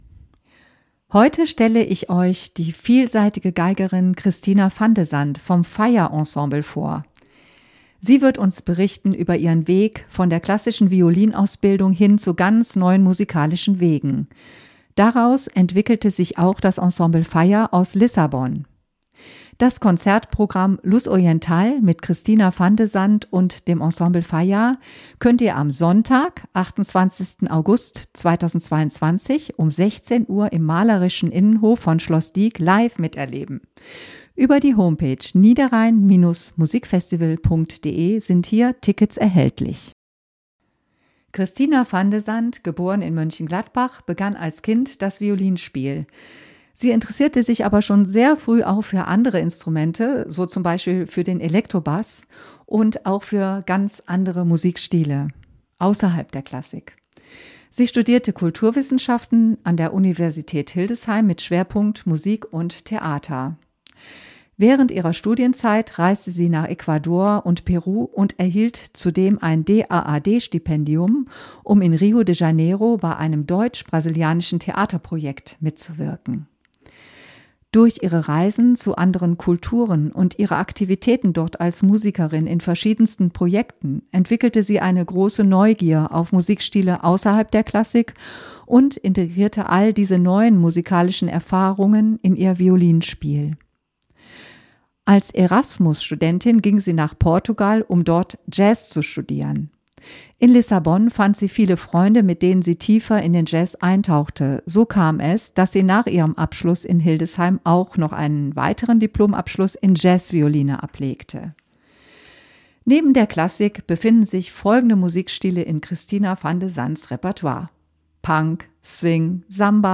003 Melodien und Rhythmen aus aller Welt | Interview